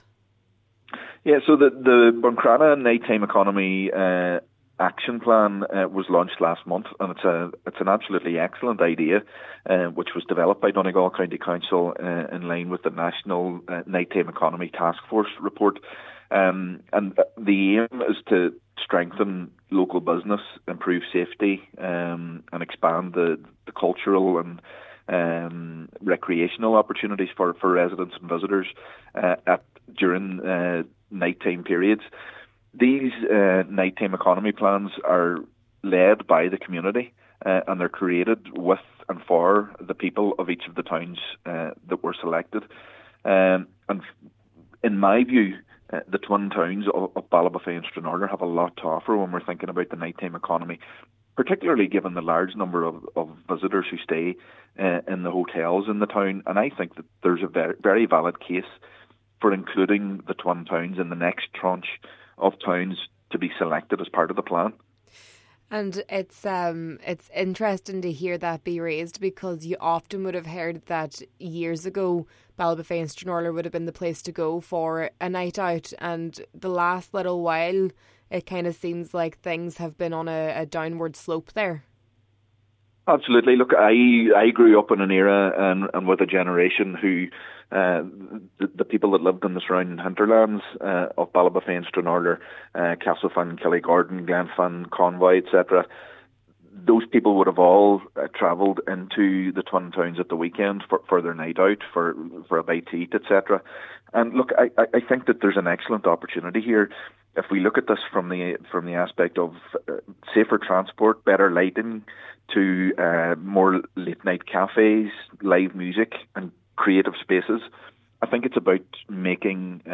Cllr Doherty said it’s time to revitalize the Twin Towns to what it once as: